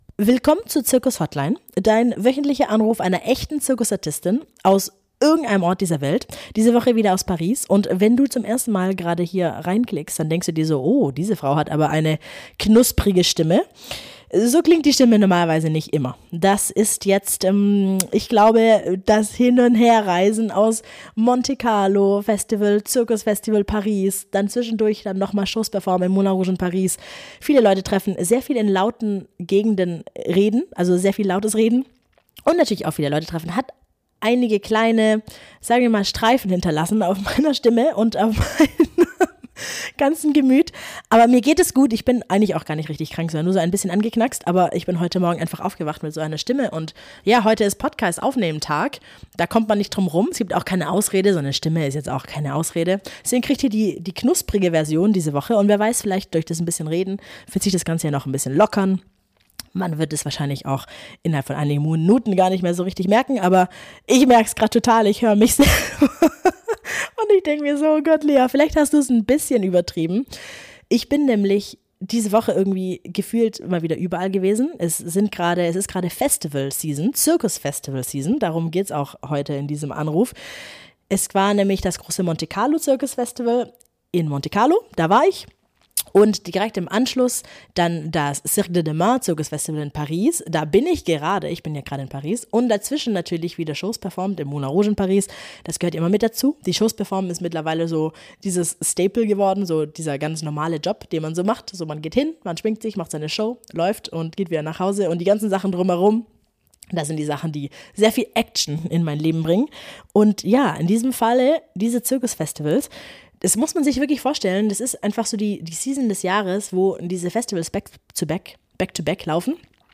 mit einer ungewohnt rauen Stimme, denn hinter ihr liegt eine intensive Festival-Woche zwischen Monte Carlo und Paris. In dieser Folge der Zirkus Hotline dreht sich alles um die große Zirkus Festival Season und um die Frage, was diese Events wirklich ausmacht.